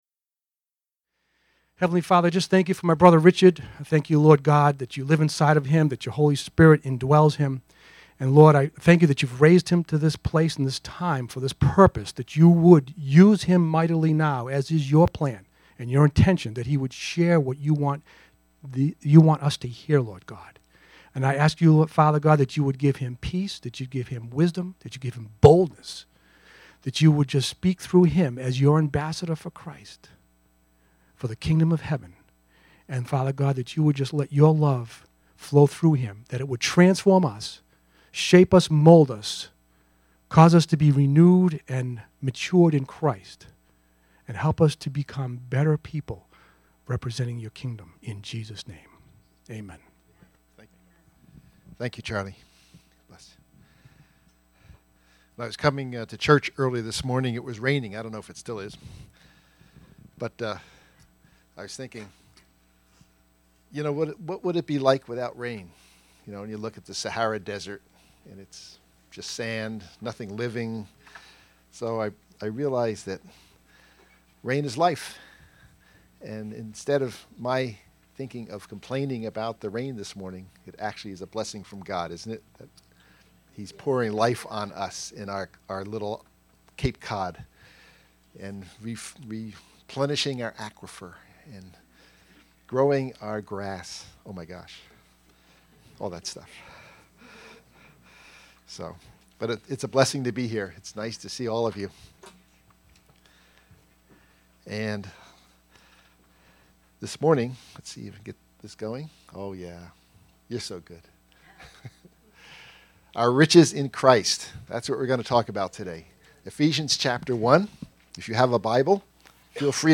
SermonCast